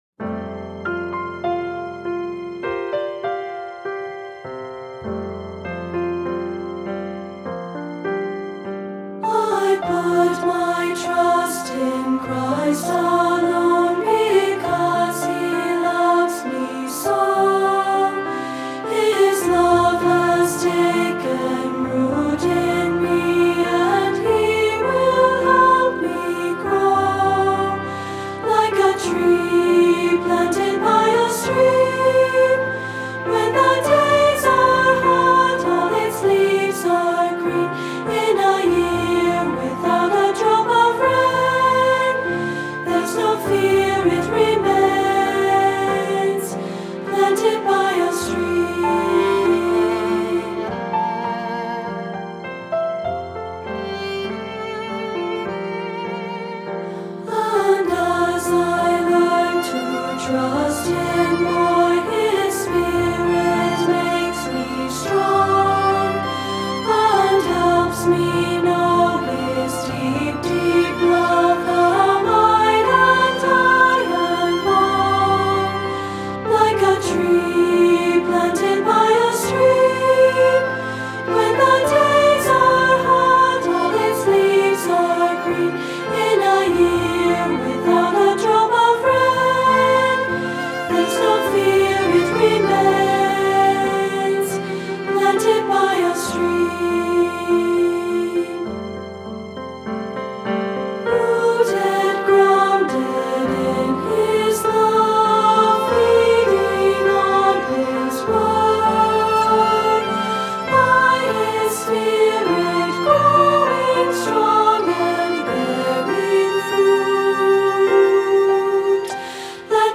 Voicing: Unison and Piano